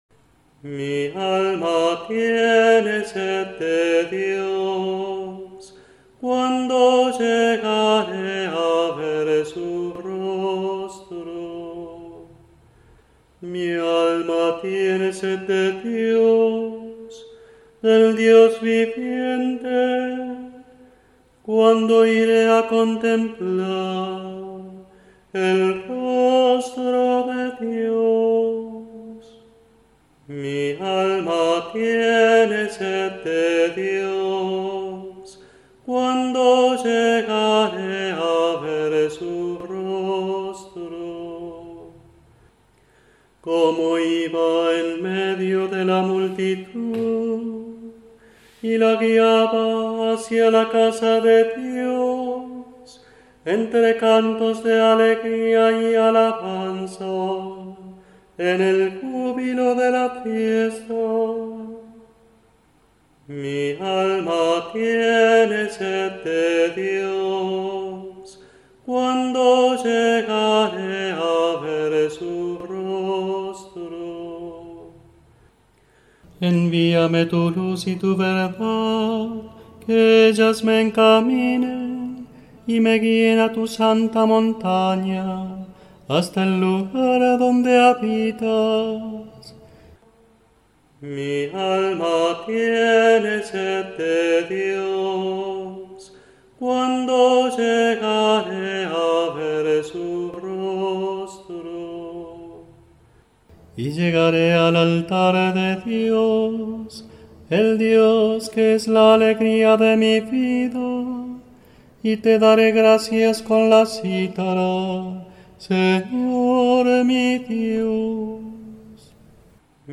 Salmos Responsoriales
Versión SATB: